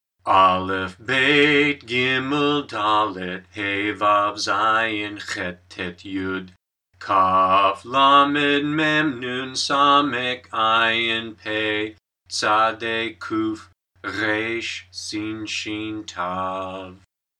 01_Hebrew Alphabet Song.mp3